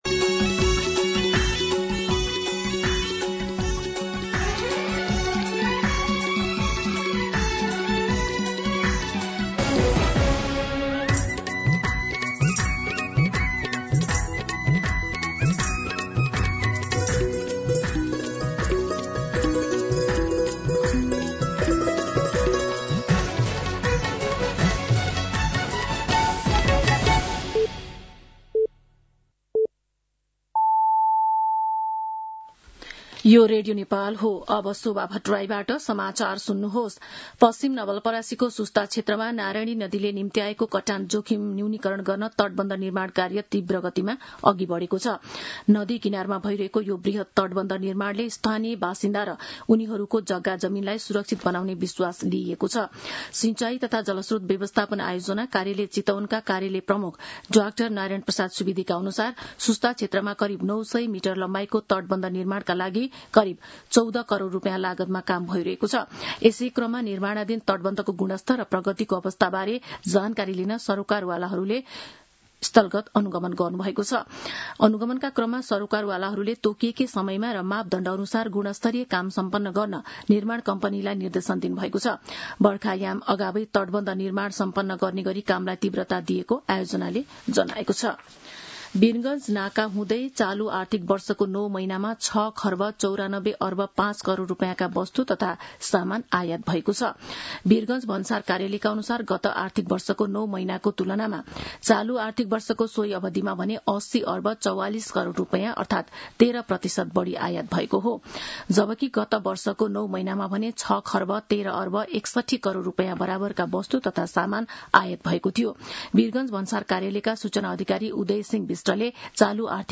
मध्यान्ह १२ बजेको नेपाली समाचार : ९ वैशाख , २०८३